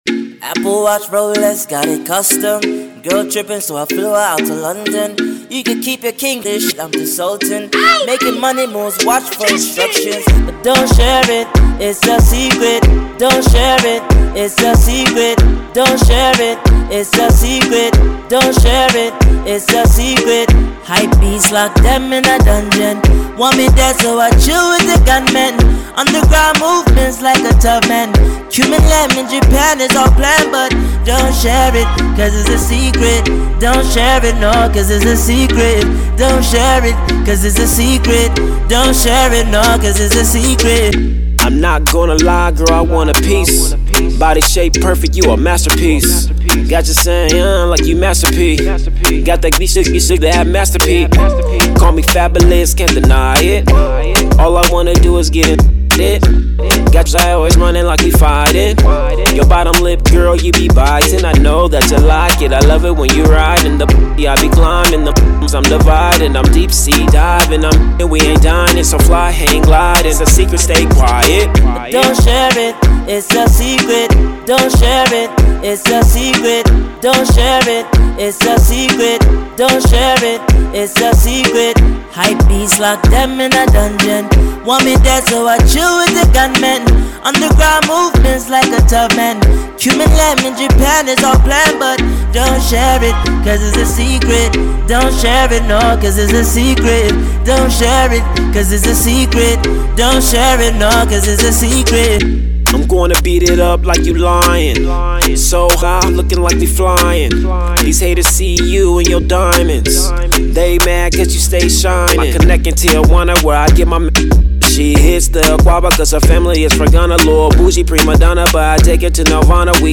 Reggeaton